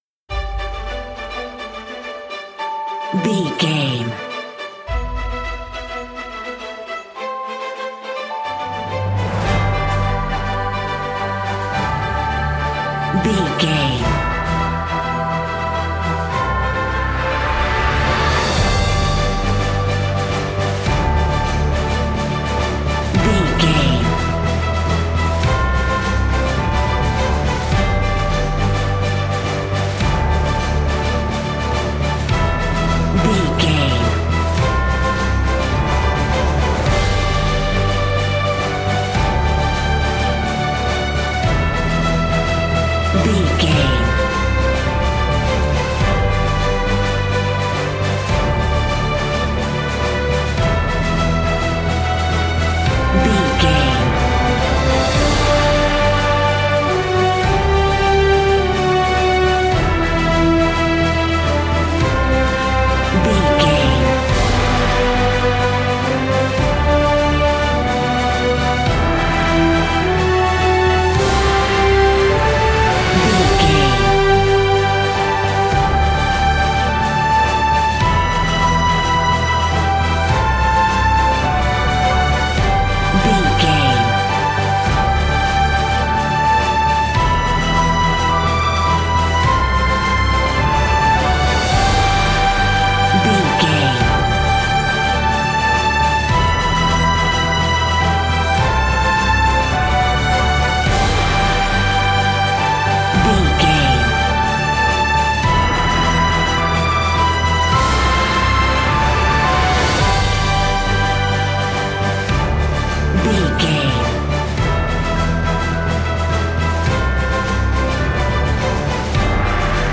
Uplifting
Ionian/Major
energetic
epic
brass
orchestra
piano
strings